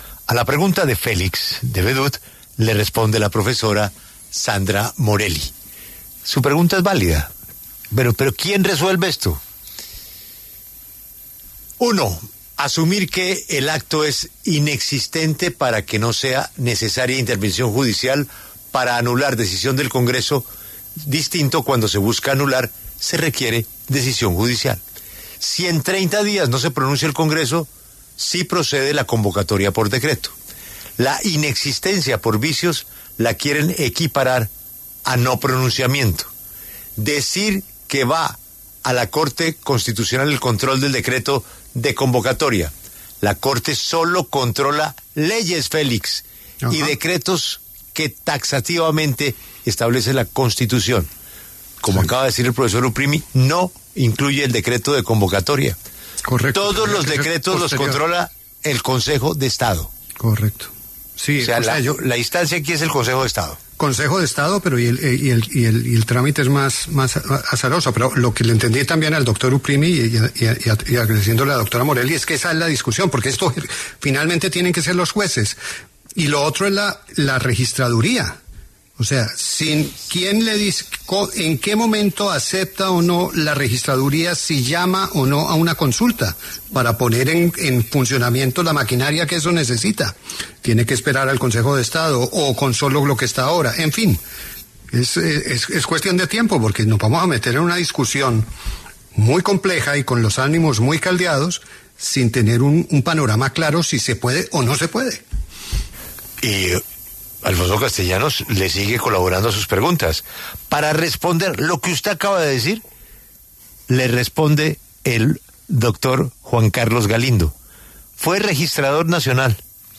La W conversó con los ex registradores nacionales Juan Carlos Galindo y Carlos Ariel Sánchez sobre la consulta popular por decreto.